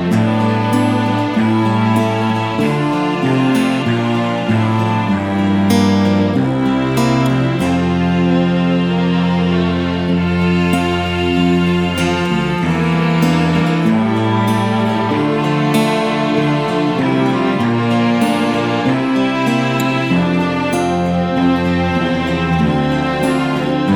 Minus Lead Guitar Pop (1960s) 2:08 Buy £1.50